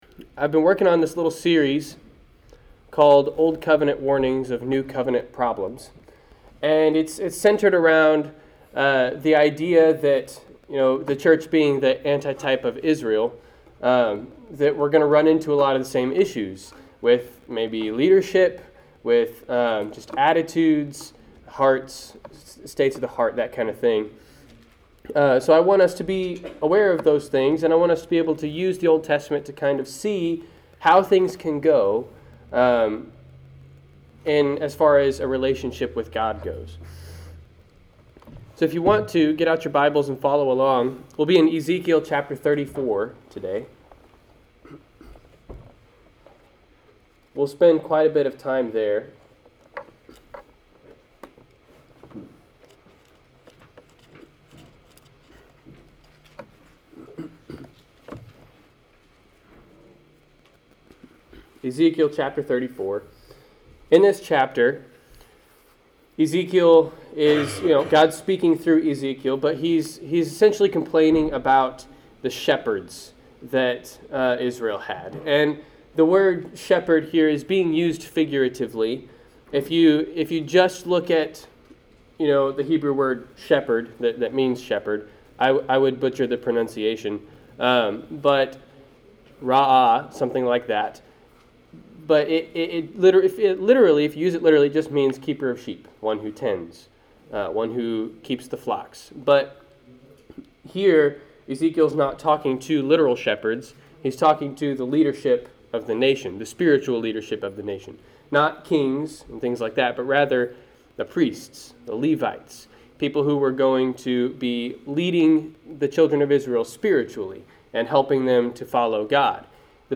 Passage: Ezekiel 34 Service Type: Sunday 10:00 AM